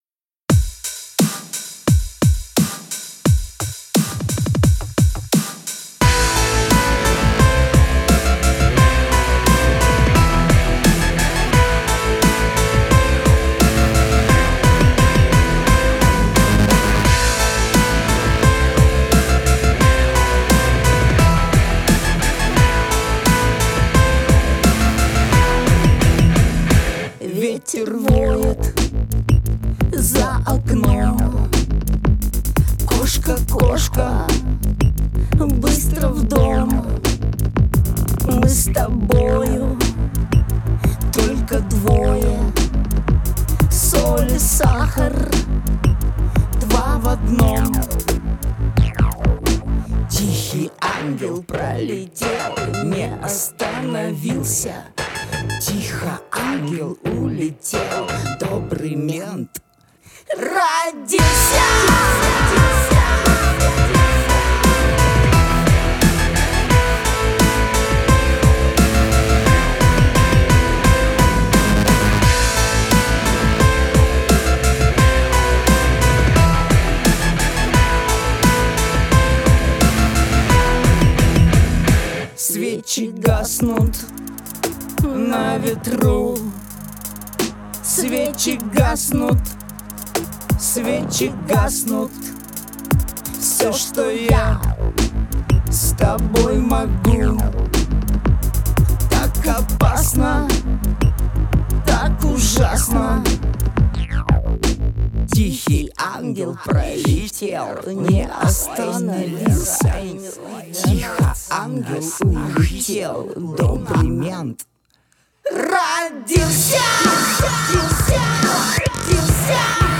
рок-музыка
Этот стиль на Руси готик-роком зовется.